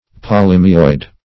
Search Result for " polymyoid" : The Collaborative International Dictionary of English v.0.48: Polymyoid \Po*lym"y*oid\, a. [Poly- + Gr.